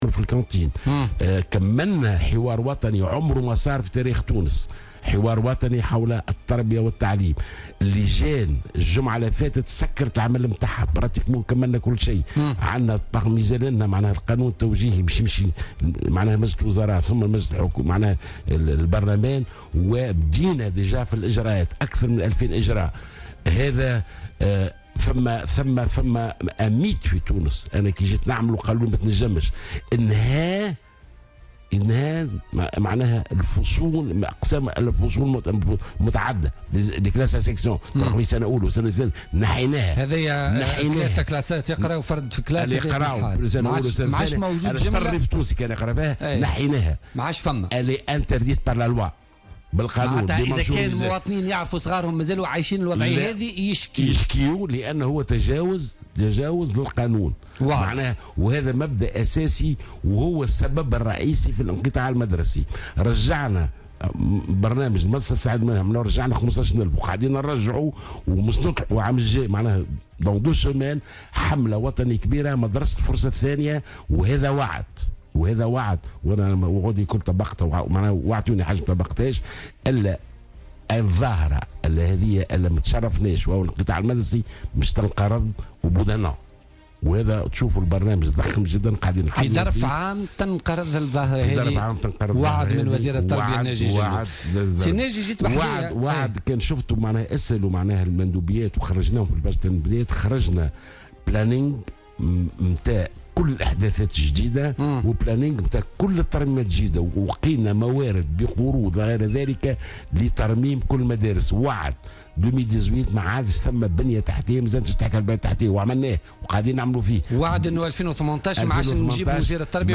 أعلن وزير التربية ناجي جلول ضيف بوليتيكا اليوم الجمعة 11 نوفمبر 2016 أن الوزارة أنهت حوارا وطنيا حول التربية والتعليم حدث لأول مرة في تاريخ تونس مشيرا إلى أن لجان الاصلاح المدرسي أنهت عملها الأسبوع الماضي وشرعت فعليا في الإجراءات.